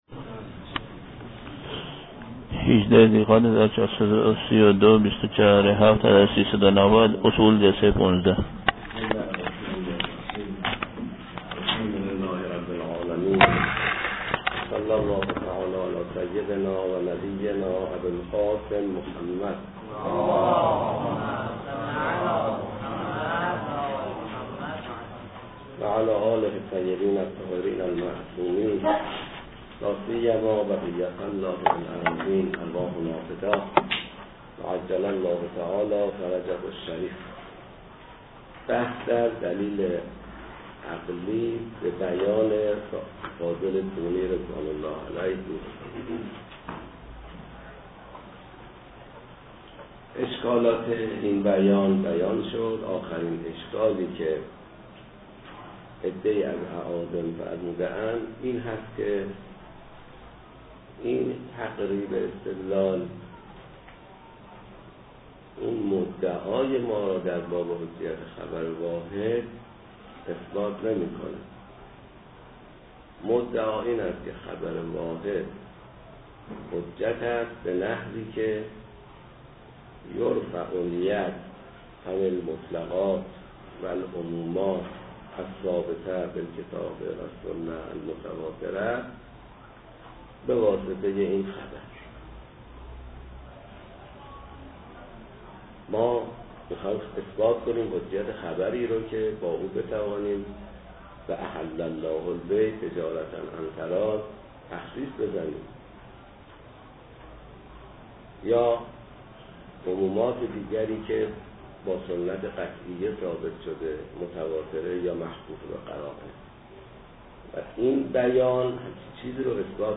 پخش آنلاین درس